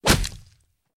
player_damage.2.ogg